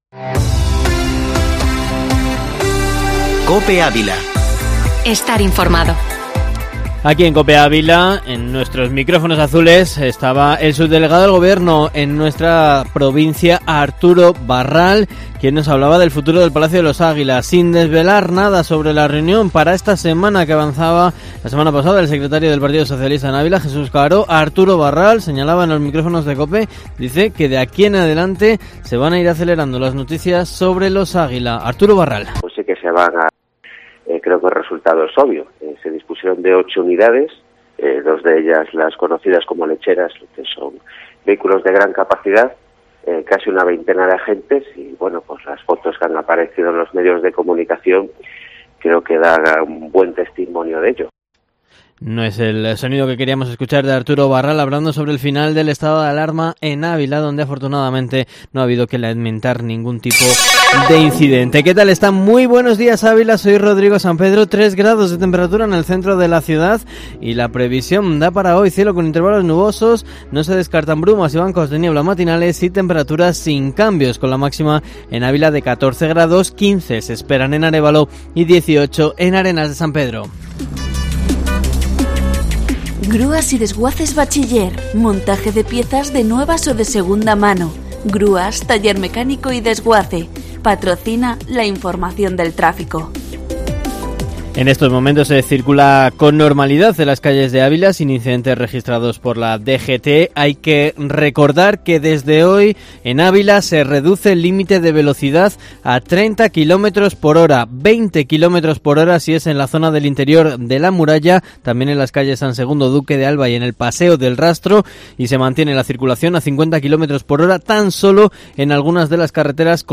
Informativo Matinal Herrera en COPE Ávila 11/05/2021